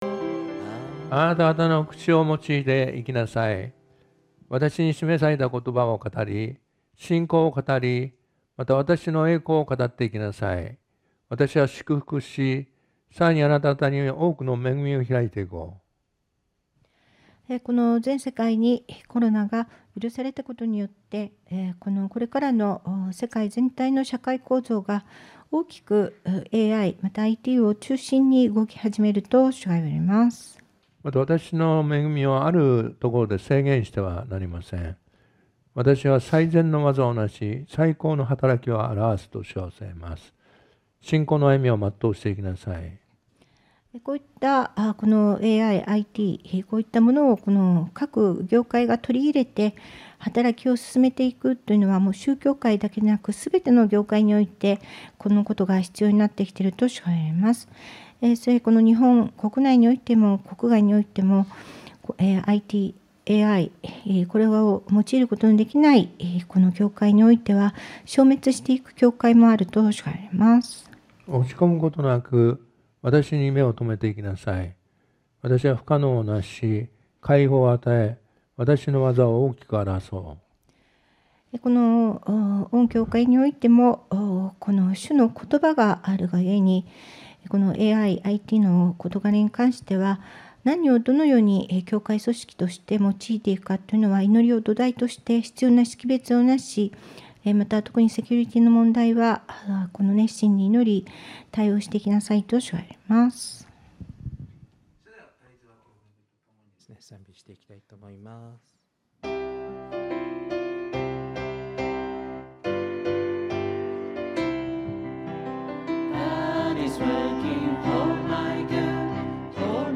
2021年度 主日礼拝保存版 – The Light of Eternal Agape 東京アンテオケ教会｜キリスト教会